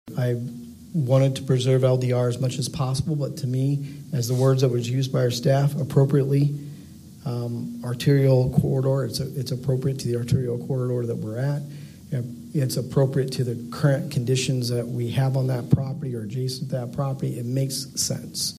Fifth Ward Councilman Scott Corbin’s sentiments echoed those of his colleagues on the governing panel.
The Holland City Council met in Chambers at Holland City Hall.